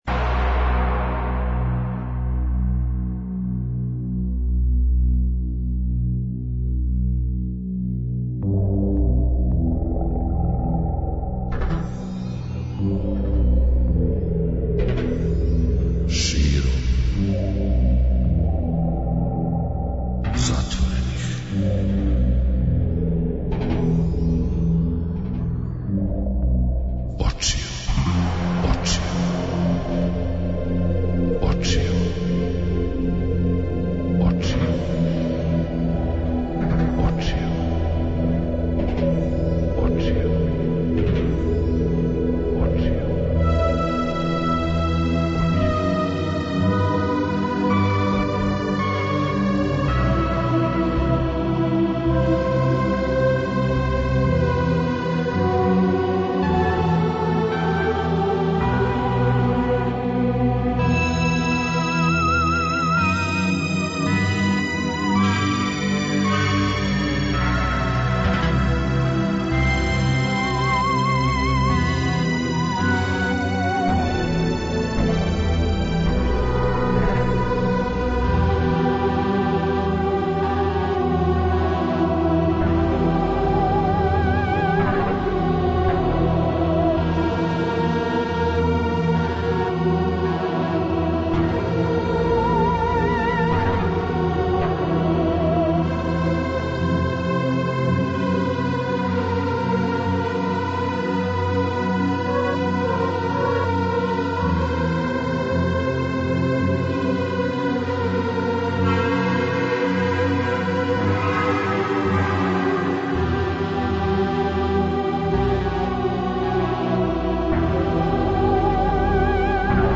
преузми : 56.29 MB Широм затворених очију Autor: Београд 202 Ноћни програм Београда 202 [ детаљније ] Све епизоде серијала Београд 202 Устанак Устанак Устанак Блузологија Свака песма носи своју причу